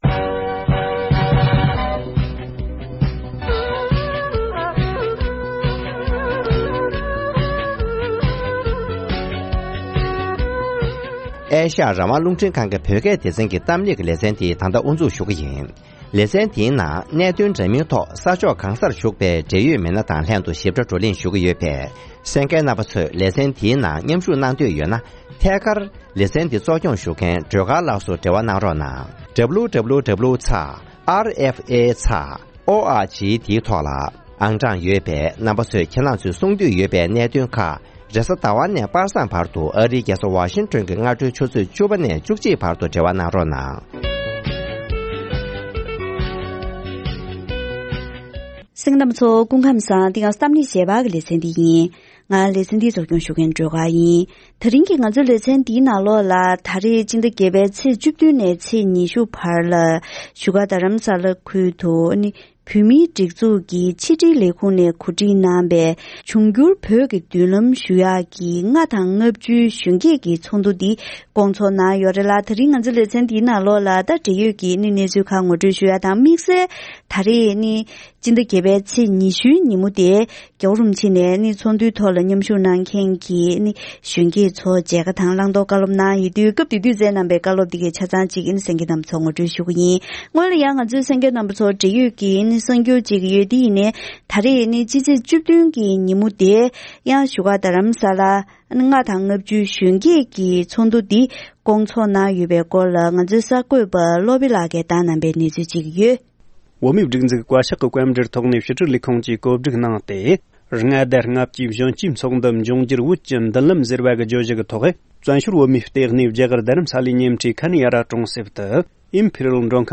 ༄༅། །ཐེངས་འདིའི་གཏམ་གླེང་ཞལ་པར་གྱི་ལེ་ཚན་ནང་དུ།